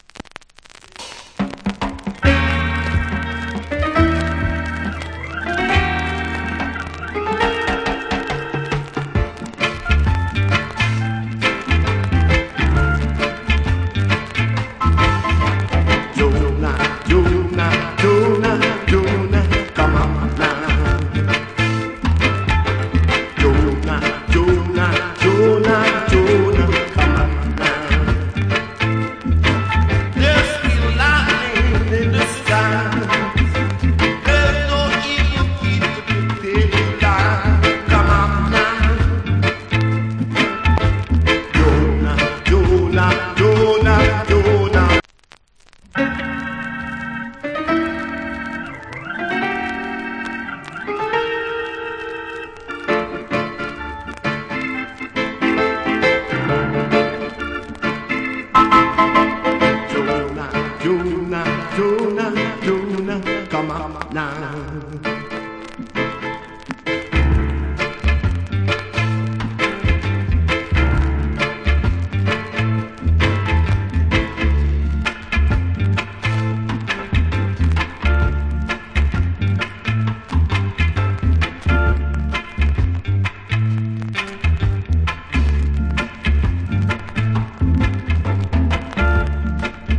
Killer Roots Rock Vocal.